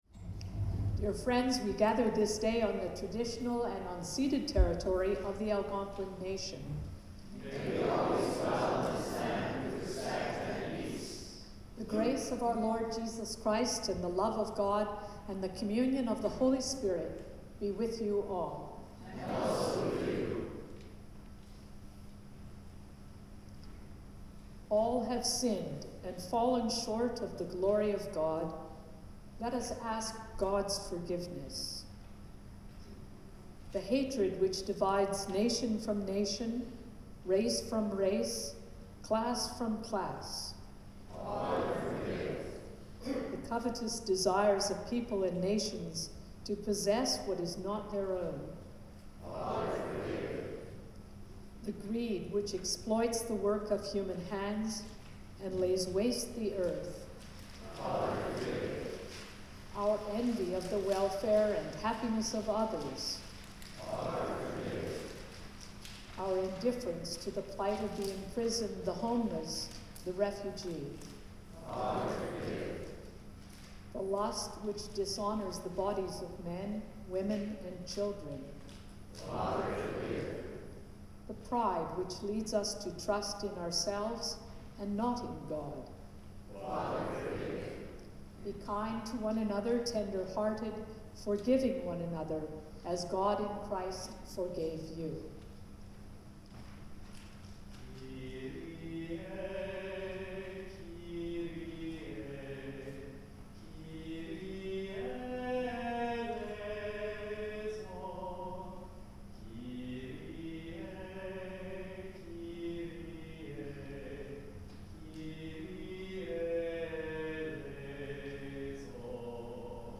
Sermons | St John the Evangelist
THIRD SUNDAY IN LENT
The Lord’s Prayer (sung)